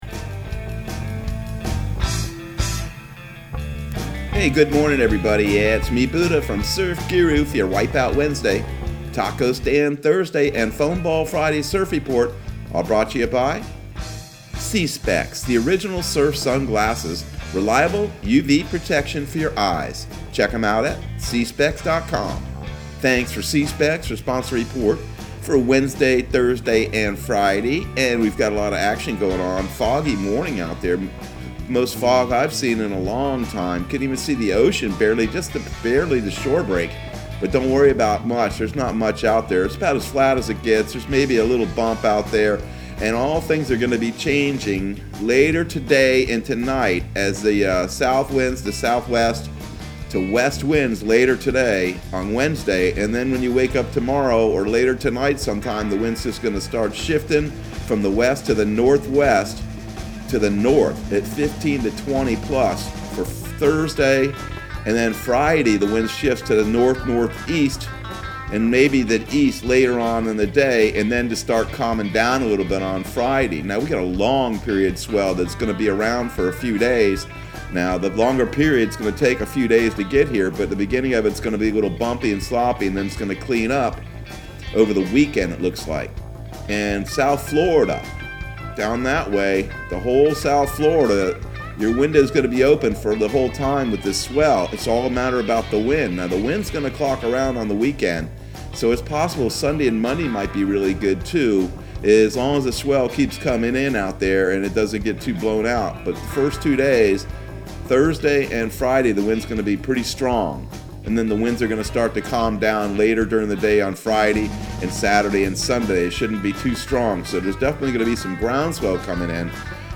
Surf Guru Surf Report and Forecast 01/27/2021 Audio surf report and surf forecast on January 27 for Central Florida and the Southeast.